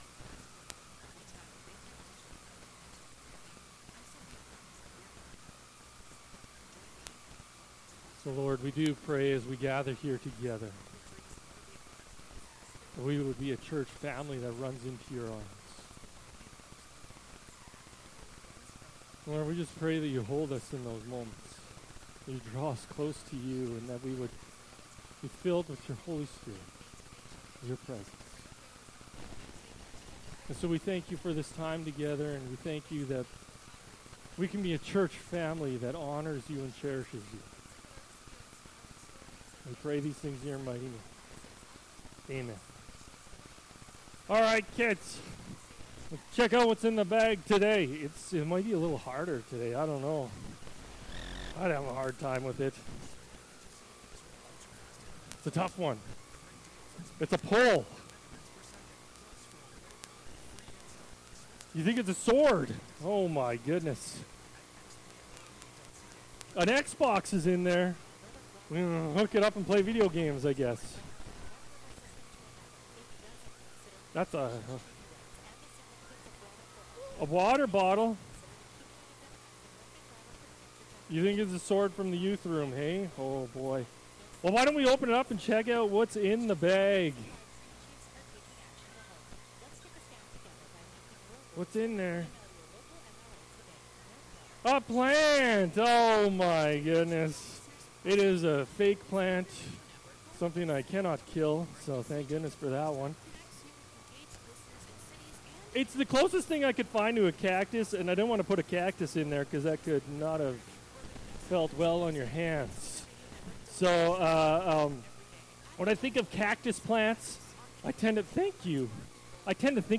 Sermons | Muir Lake Community Alliance Church